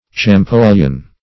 champollion - definition of champollion - synonyms, pronunciation, spelling from Free Dictionary